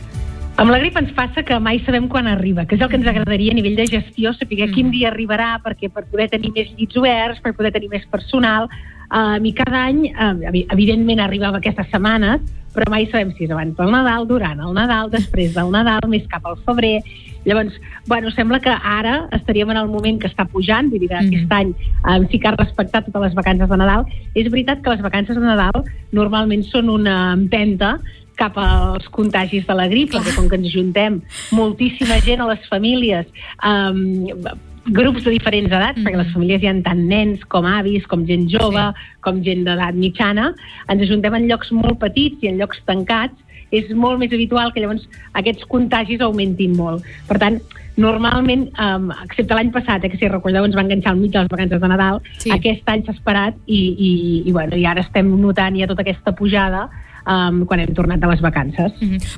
Entrevistes Supermatí
Supermatí - entrevistes